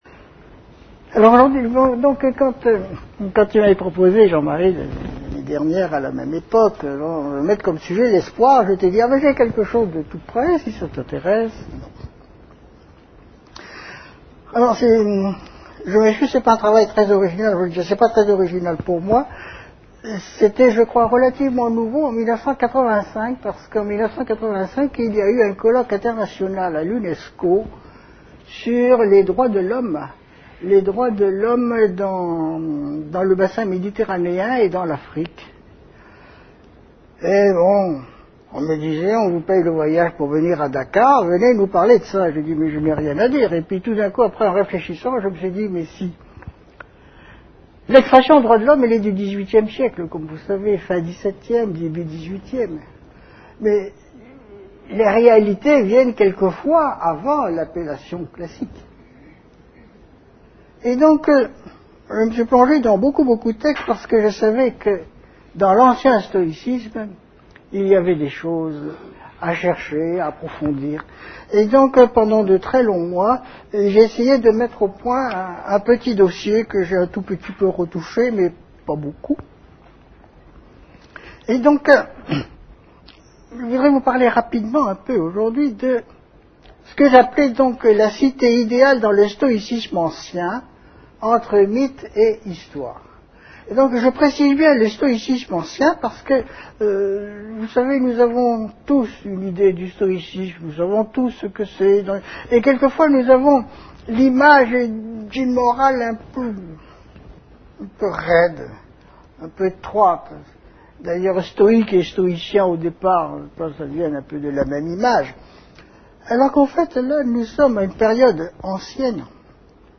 Mais quels sont les principes de cette cité idéale stoïcienne ? et, sa réalité ne relève t-elle pas du mythe ? La conférence a été donnée à l'Université Victor Segalen Bordeaux 2 dans le cadre du cycle de conférences "L'invité du Mercredi" / Saison 2005-2006 sur le thème "L'espoir".